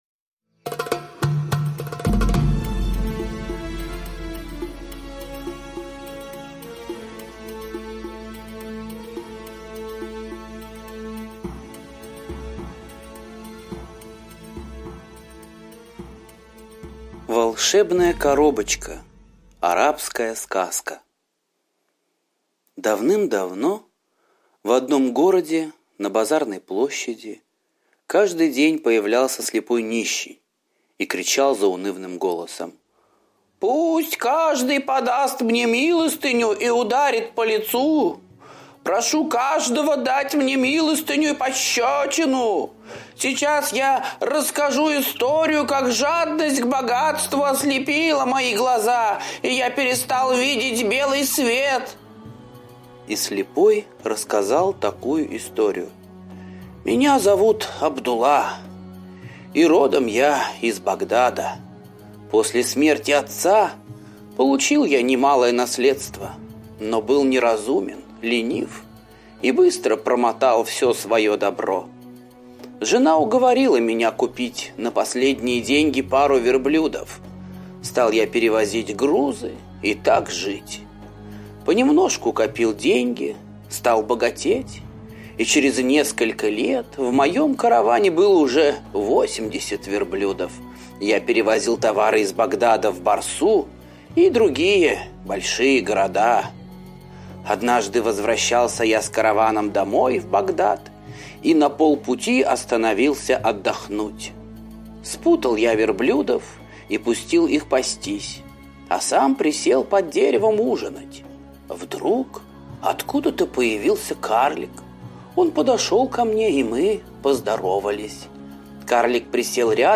Волшебная коробочка - восточная аудиосказка - слушать онлайн